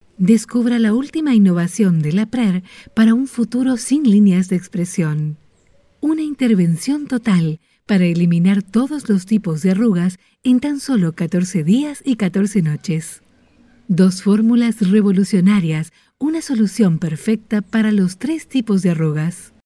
Voice Sample: Voice Sample
We use Neumann microphones, Apogee preamps and ProTools HD digital audio workstations for a warm, clean signal path.